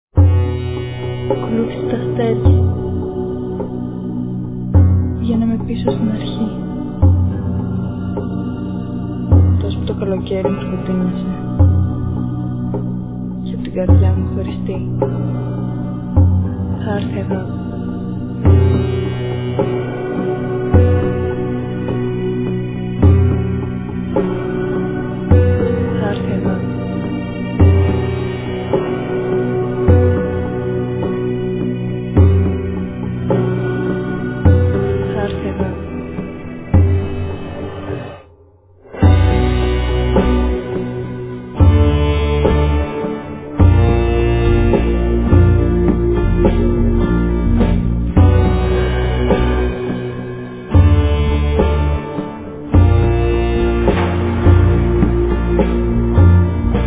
tambouras, vocals
flute, clarinet
mandolina, viola
harp
classical guitar
drums